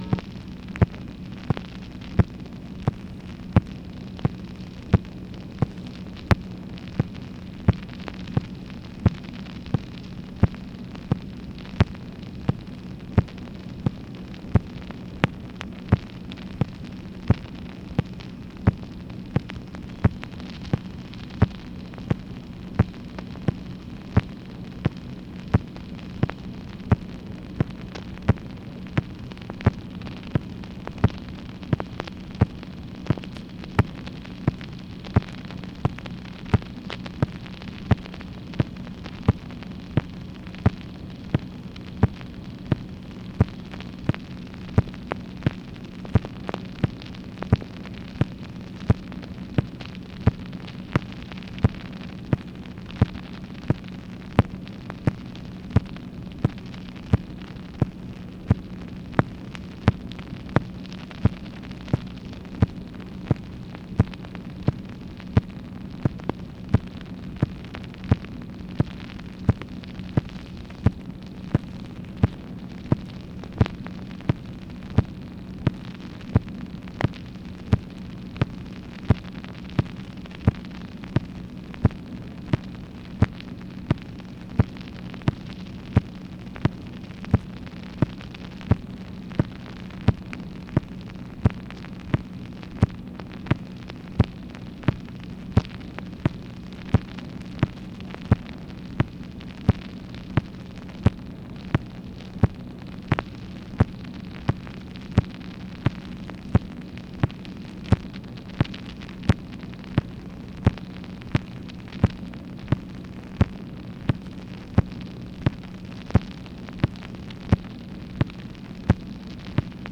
MACHINE NOISE, June 22, 1965